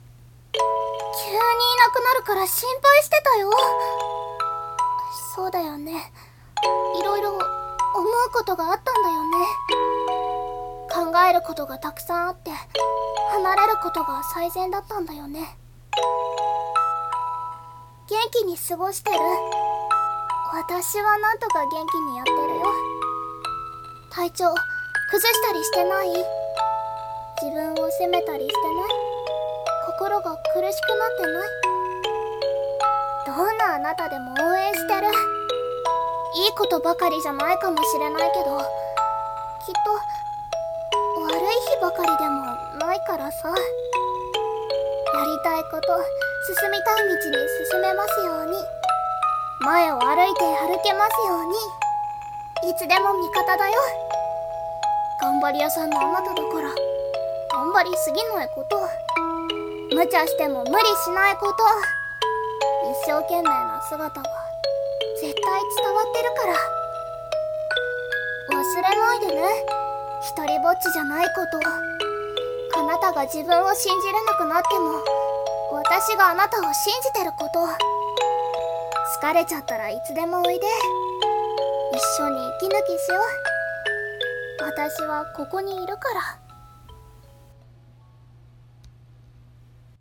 【声劇台本】大切なあなたへ。